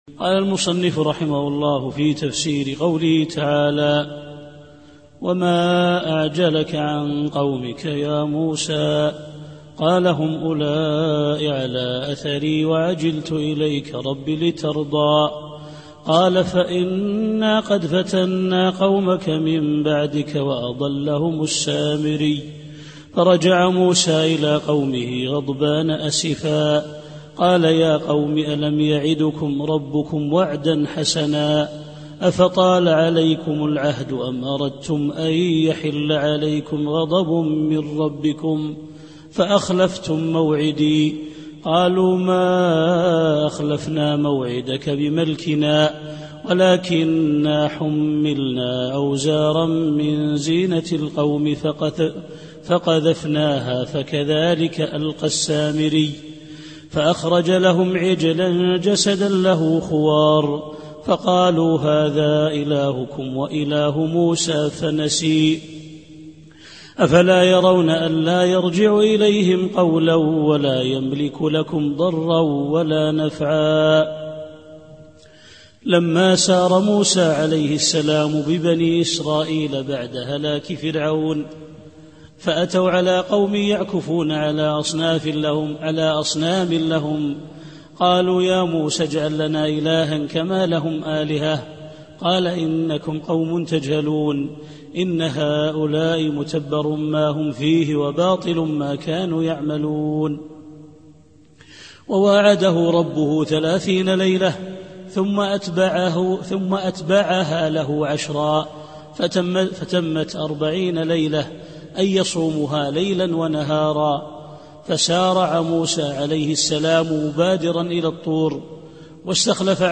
التفسير الصوتي [طه / 89]